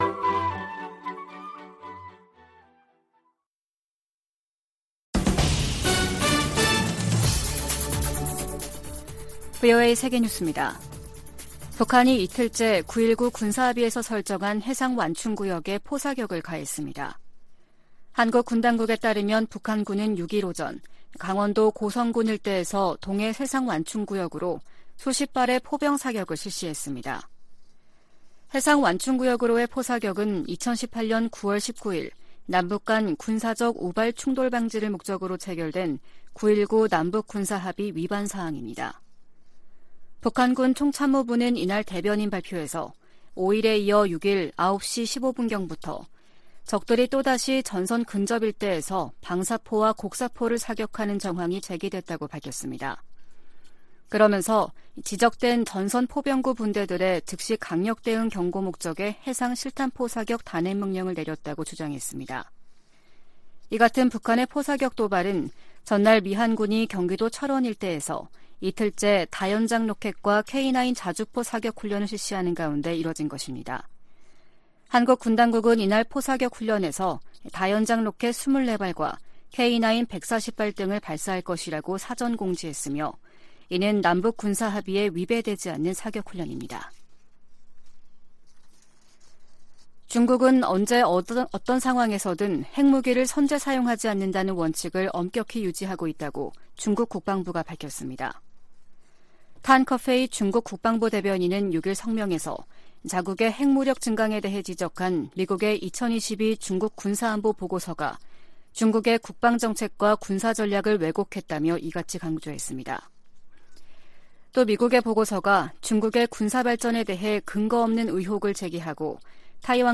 VOA 한국어 아침 뉴스 프로그램 '워싱턴 뉴스 광장' 2022년 12월 7일 방송입니다. 북한 김정은 정권이 미군과 한국 군의 통상적인 훈련을 구실로 이틀 연속 9.19 남북 군사합의를 위반하며 해상완충구역을 향해 포 사격을 가했습니다. 중국이 미중 정상회담 이후에도 여전히 북한 문제와 관련해 바람직한 역할을 하지 않고 있다고 백악관 고위 관리가 지적했습니다.